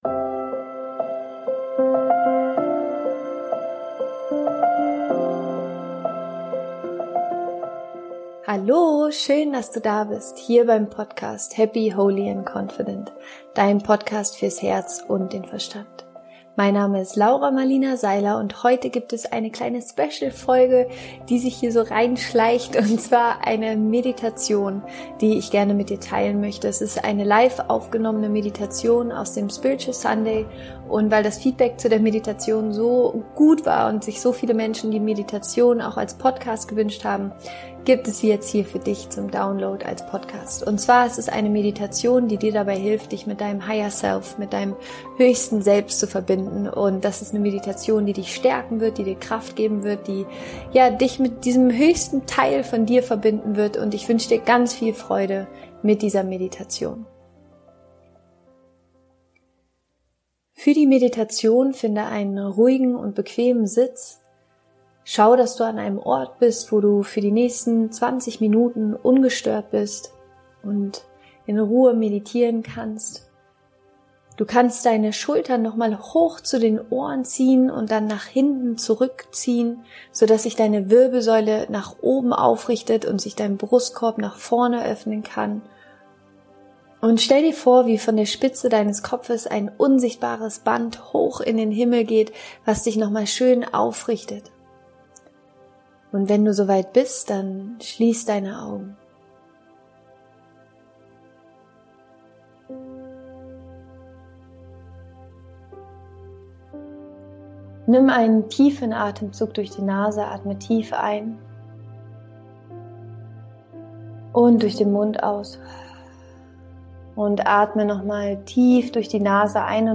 Setze dich bequem hin und schließe deine Augen. Die geleitete Meditation bringt dich in die tiefe Verbundenheit mit deinem Higher Self.
Geleitete Meditation: Verbinde dich mit dem Higher Self